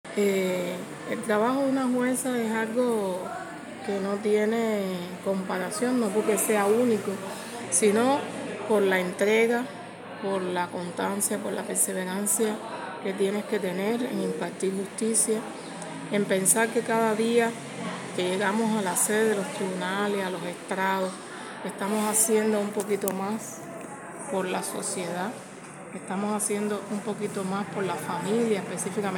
Entrevista Matanzas